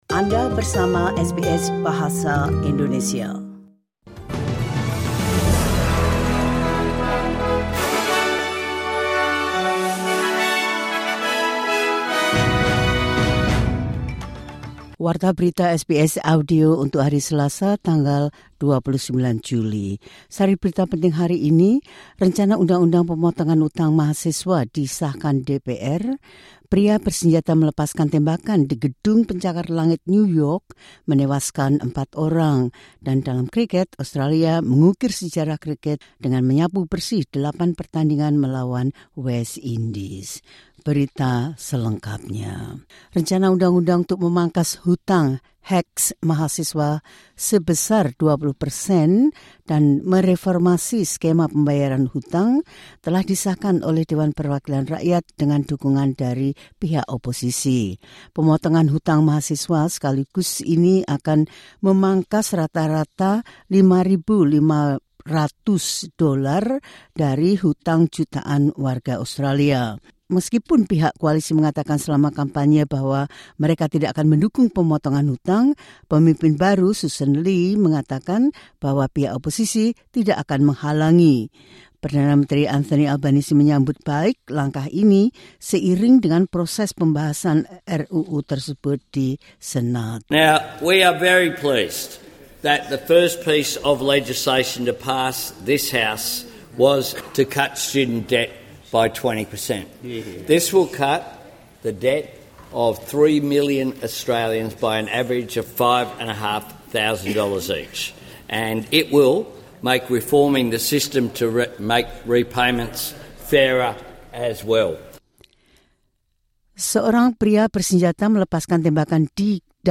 The latest news SBS Audio Indonesian Program – 29 Jul 2025.